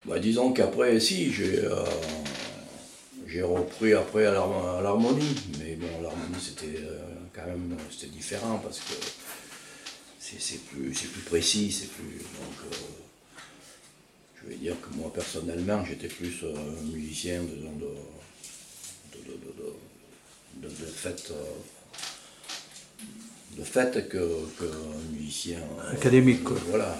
Enquête Enquête ethnologique sur les fêtes des bouviers et des laboureurs avec l'aide de Témonia
Catégorie Témoignage